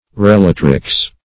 relatrix - definition of relatrix - synonyms, pronunciation, spelling from Free Dictionary Search Result for " relatrix" : The Collaborative International Dictionary of English v.0.48: Relatrix \Re*lat"rix\ (-r?ks), n. [L.]